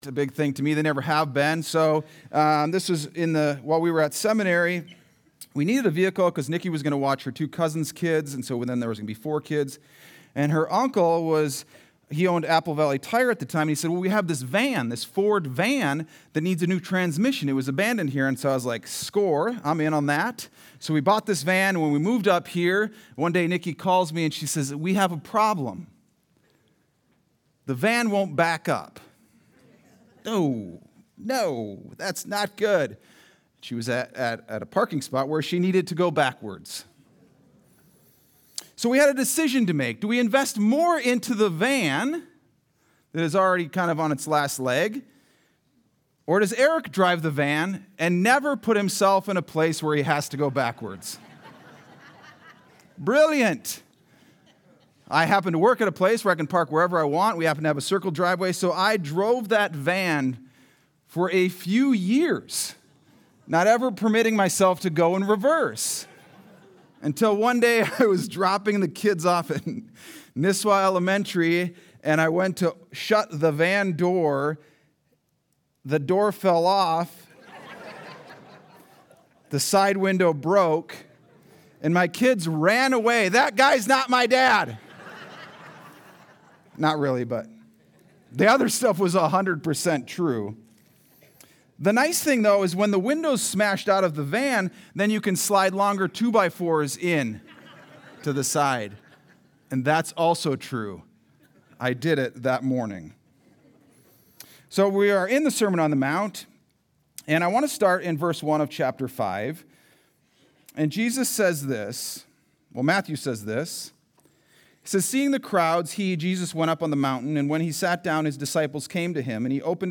Sunday Sermon: 7-13-25